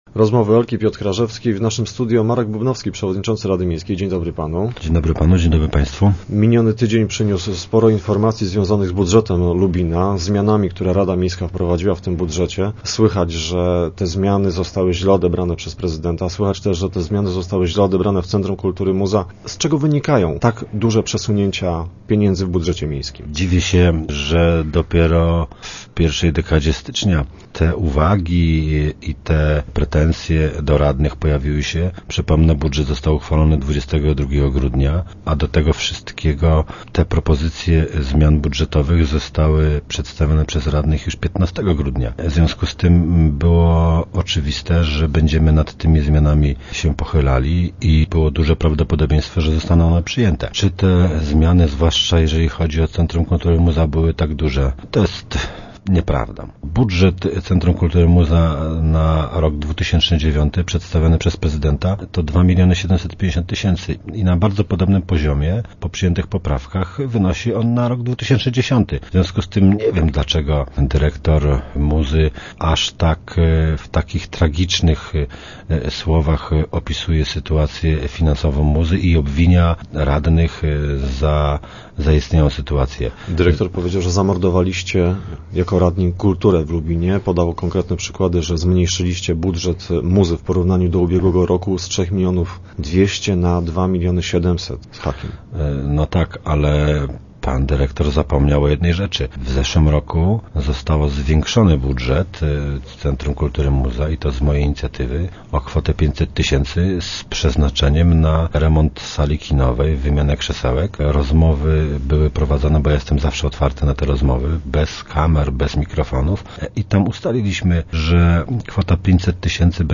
Rada Miejska zmieniła plan dotacji dla tej jednostki o pół miliona zł. Dlaczego i czy można jeszcze zmienić dotacje dla Muzy o tym rozmawialiśmy z Markiem Bubnowskim, przewodniczącym rady Miejskiej w Lubinie.